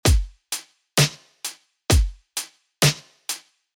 Hit “play” below to see what this pattern would sound like when played on a drum set.
This audio example corresponds to the pattern depicted, giving you an auditory reference for what the combination of these blocks – and thus the instruments and rhythm they represent – should sound like when played on a drum set.